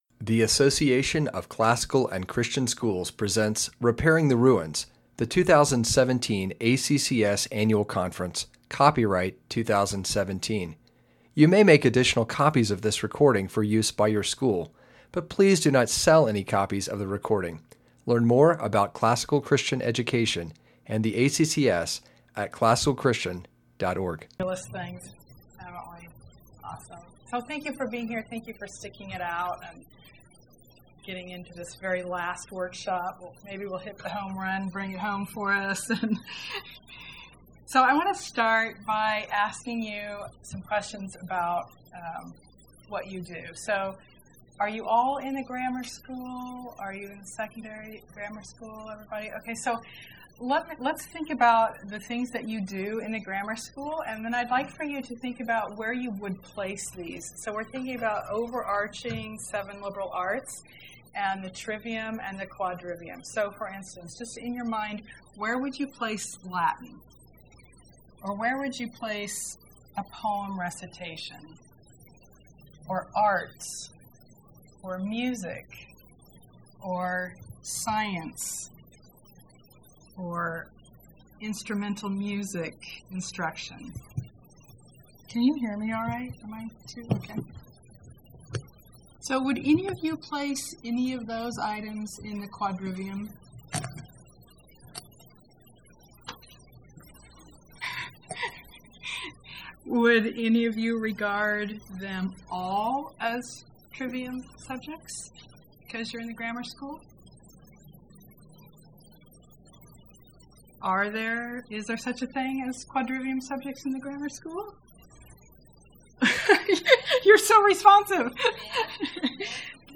2017 Workshop Talk | 0:50:53 | K-6, Math, Quadrivium, Science
Speaker Additional Materials The Association of Classical & Christian Schools presents Repairing the Ruins, the ACCS annual conference, copyright ACCS.